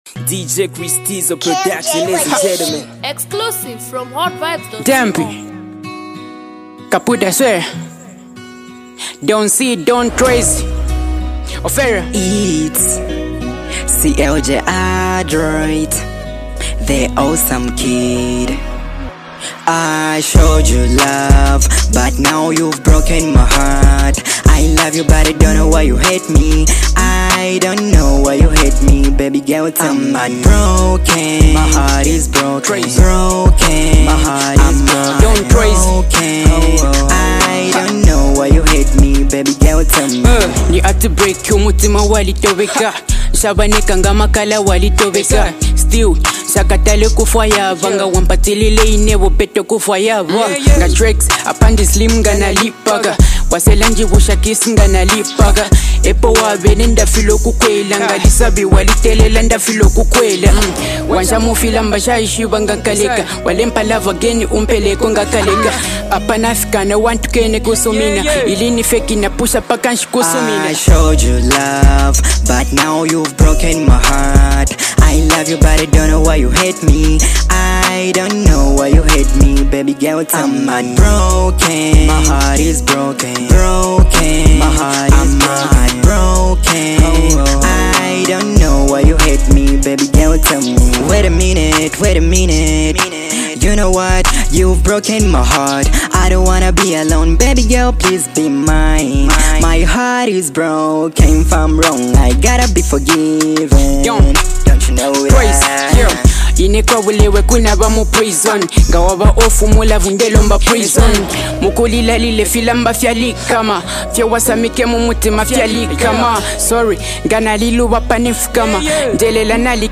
love song